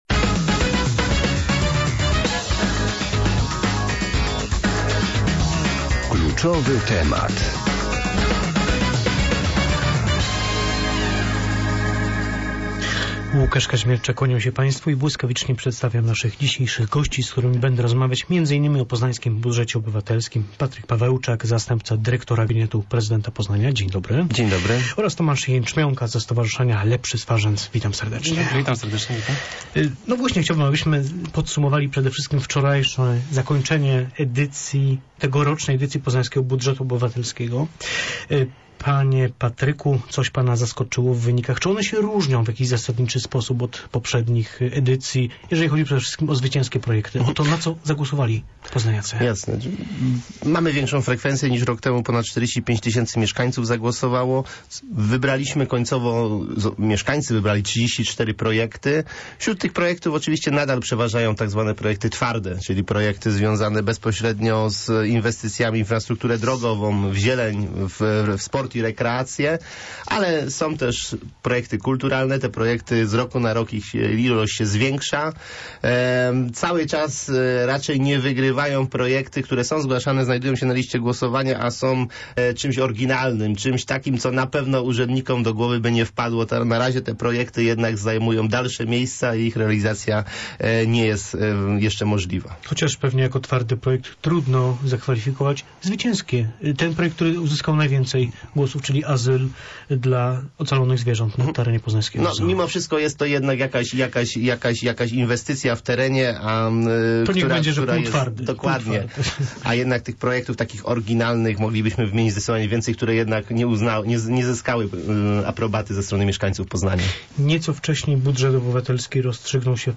Więcej o Budżetach Obywatelskich w rozmowie